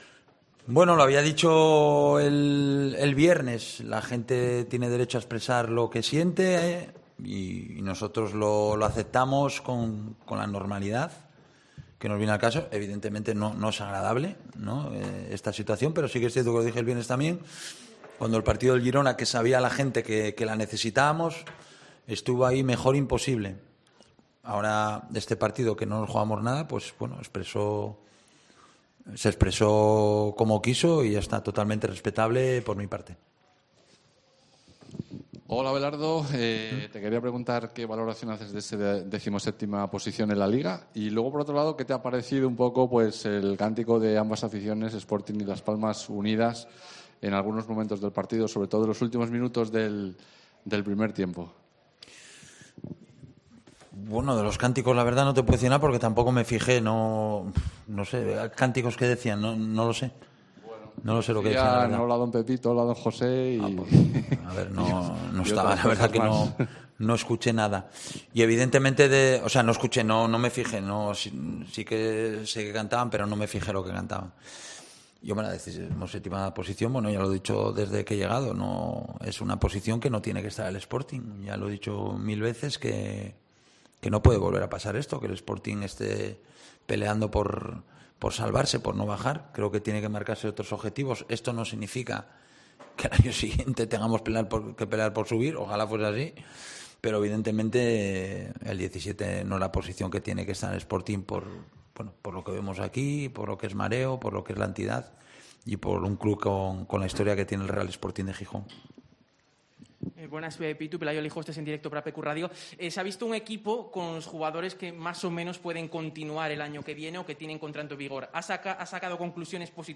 Rueda de prensa Abelardo (post Las Palmas)